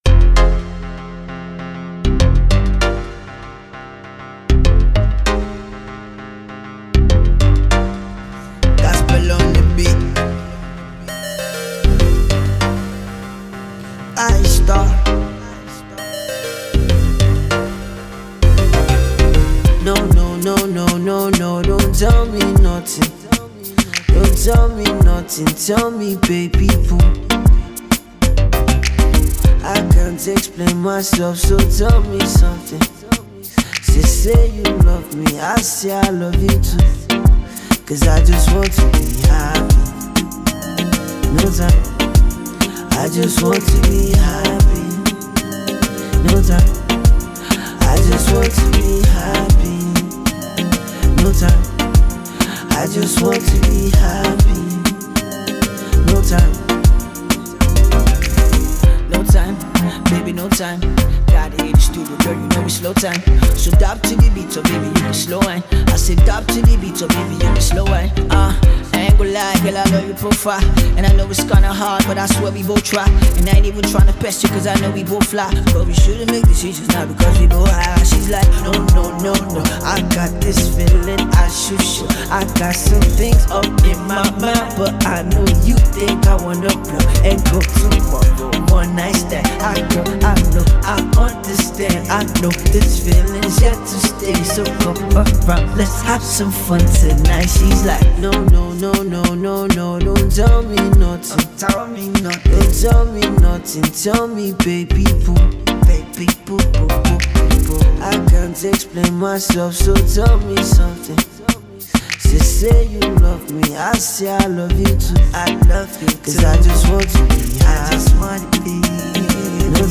has a fusion of rap and vocals